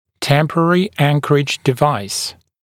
[‘tempərərɪ ‘æŋkərɪʤ dɪ’vaɪs][‘тэмпэрэри ‘энкэридж ди’вайс]устройство для временной опоры (мини-имплантат, мини-пластина)